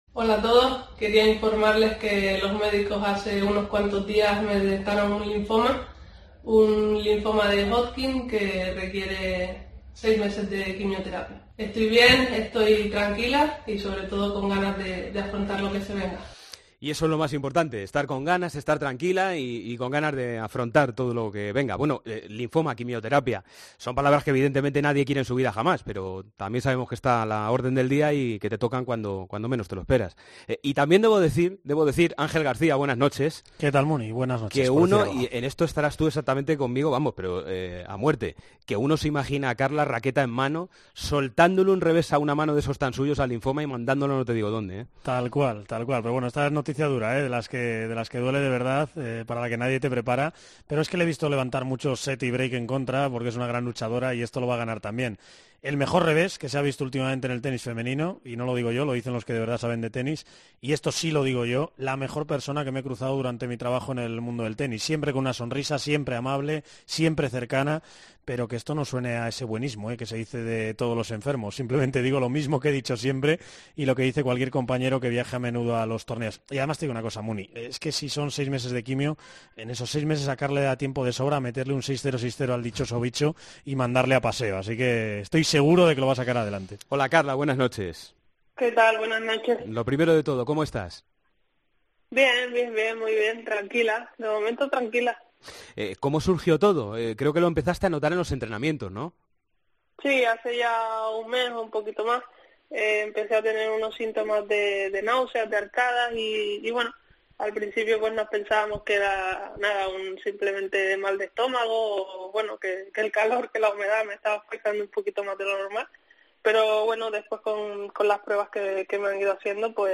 Entrevista a la tenista española, que sufre un linfoma de Hodgkin y se someterá a quimioterapia próximamente.